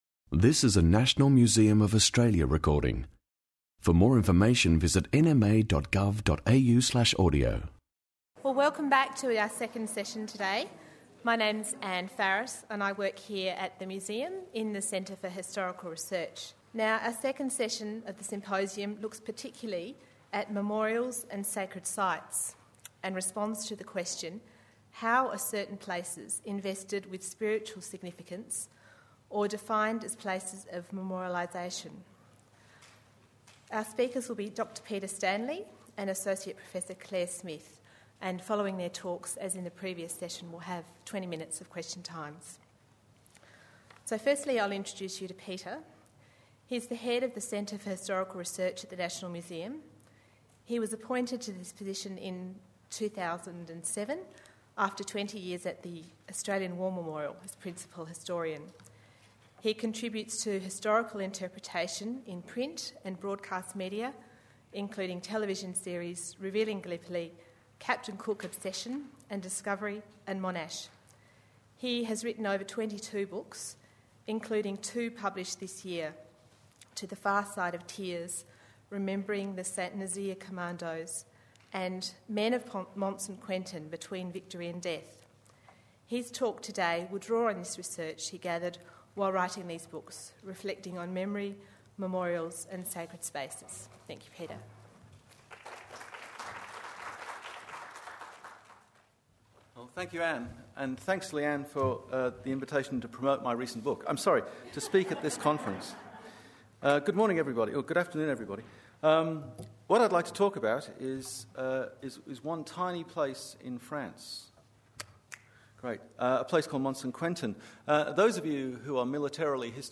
Sites of Memory symposium 28 Aug 2009